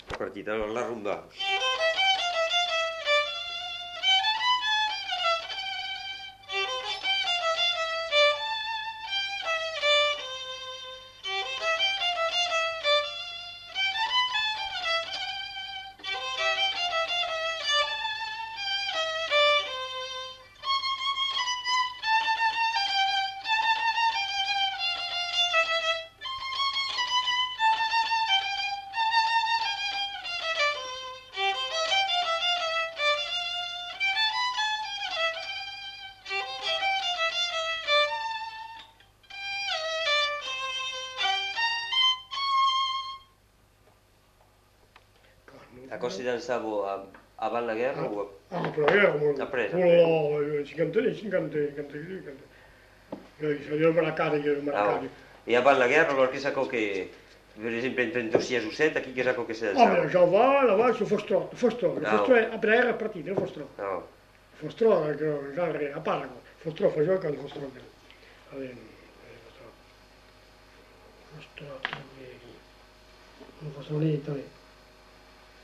Genre : morceau instrumental
Instrument de musique : violon
Danse : rumba
Ecouter-voir : archives sonores en ligne